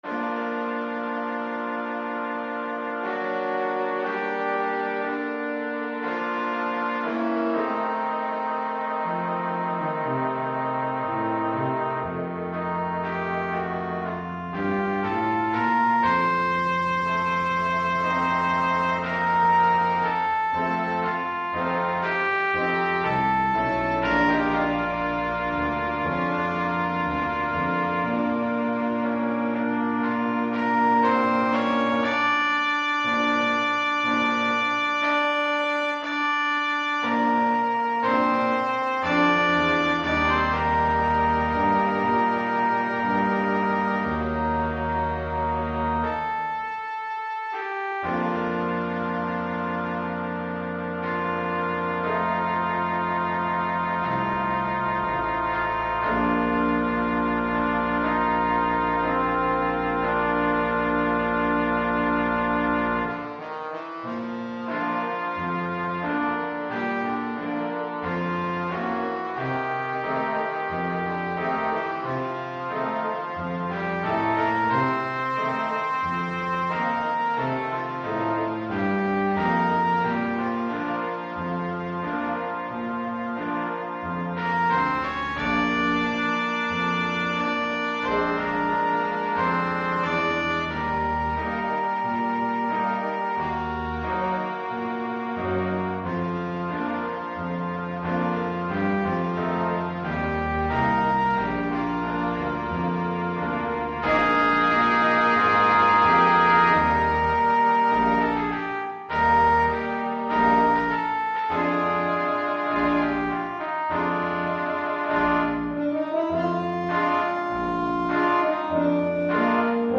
Gattung: 5-Part Ensemble
Besetzung: Ensemble gemischt
Piano, Guitar & Drums optional.